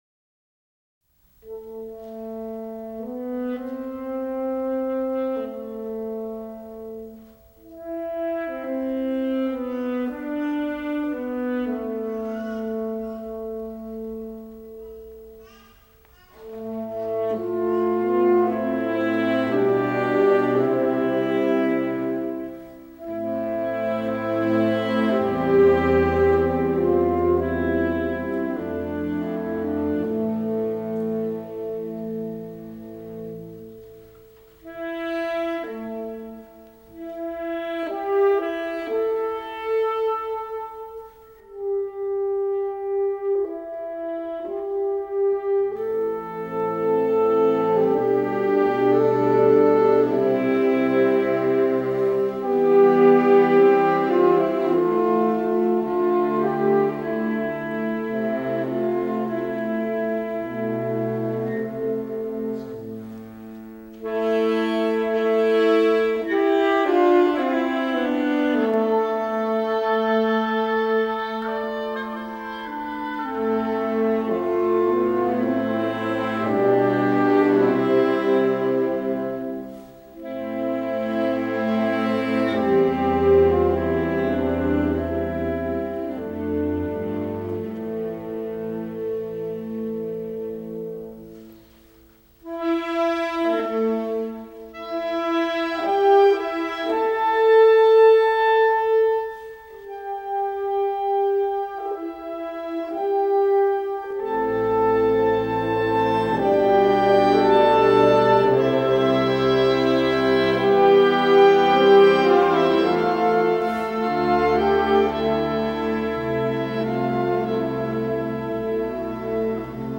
A minor（原調）
哀愁に満ちた味わい深い音楽です。編成は木管楽器のみ。金管楽器と打楽器は使いません。